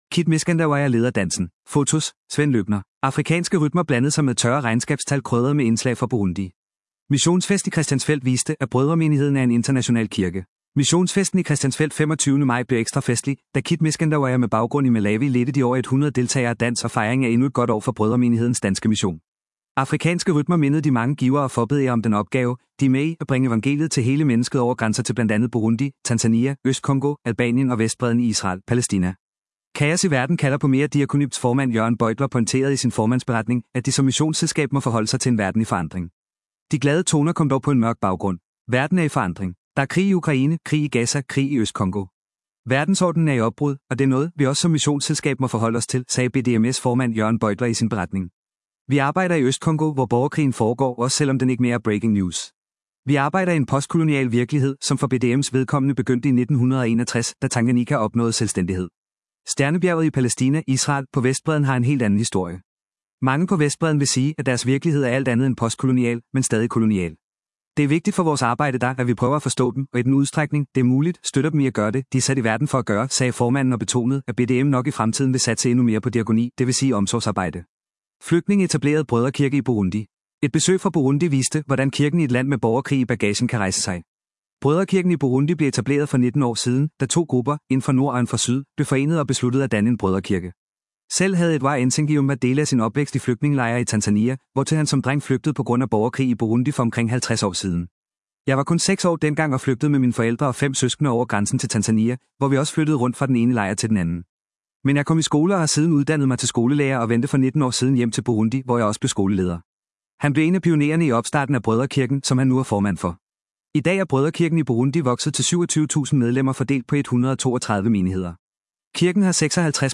Afrikanske rytmer blandede sig med tørre regnskabstal krydret med indslag fra Burundi. Missionsfest i Christiansfeld viste, at Brødremenigheden er en international kirke.